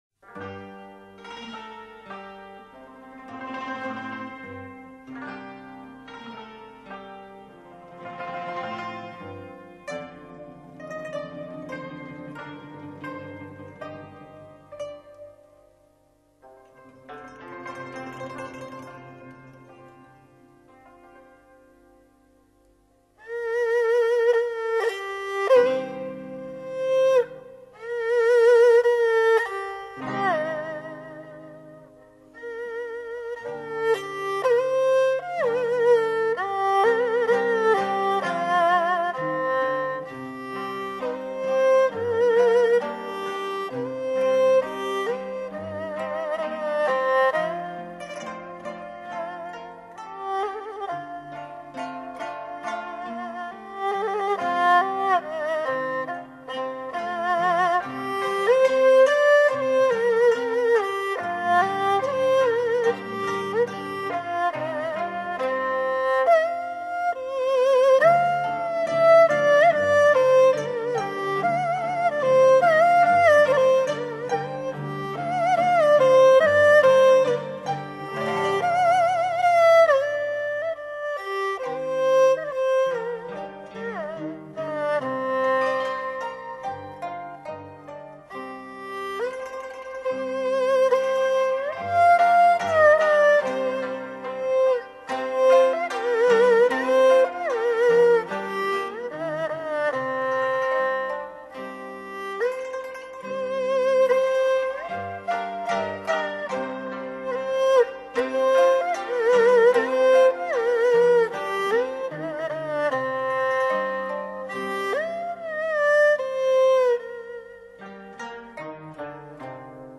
特殊的揉弦技术如搂弦技法的使用，用于强调极具特色的哭音（又称苦音）和欢音（又称花音）的效果。